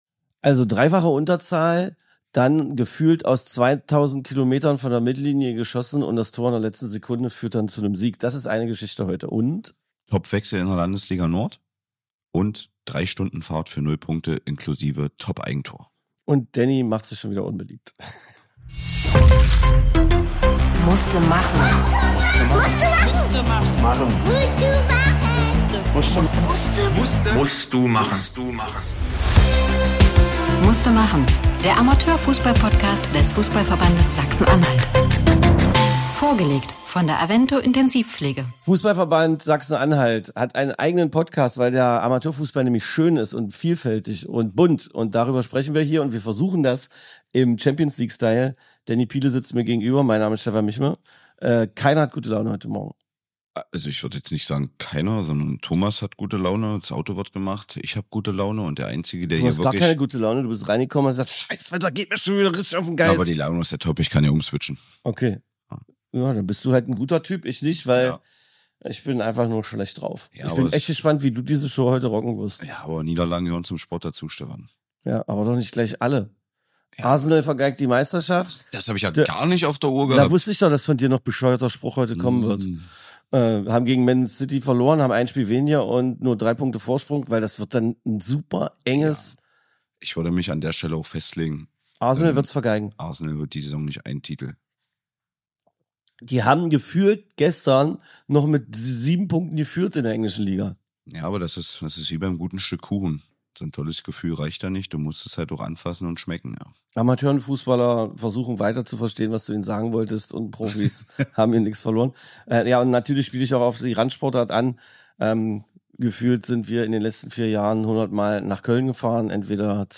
Zwischen ernsthaften Themen und echtem Fußball-Alltag wird es immer wieder bewusst albern.
Diese Folge ist vielleicht die albernste bisher – und genau deshalb besonders.